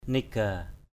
/ni-ɡ͡ɣa:˨˩/ (Pa. niggato) (đg.) rời, từ bỏ, bỏ lại = quitter, laisser.